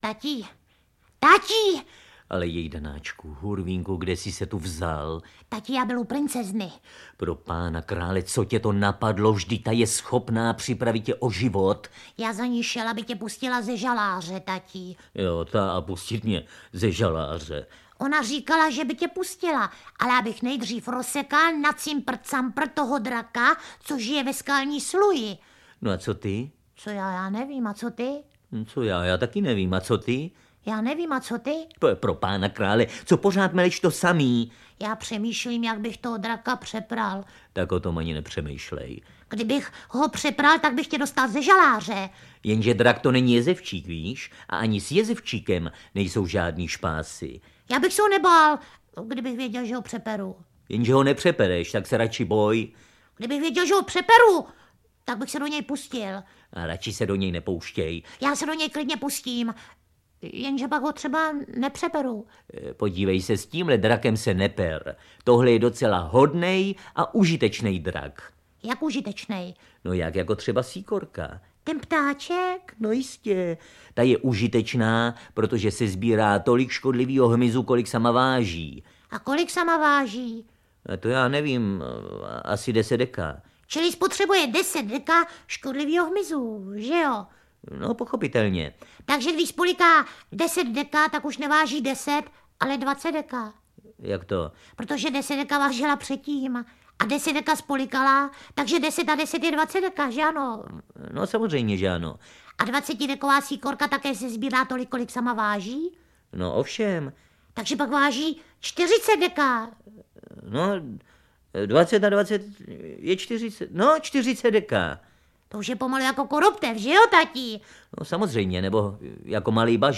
Na malé i velké posluchače čekají v tomto dílu pohádky vyprávěné renomovanými herci jako např. Milošem Kopeckým (Sloní mládě), Václavem Postráneckým (O třech malých princezničkách), Jaroslavem Kepkou…
Ukázka z knihy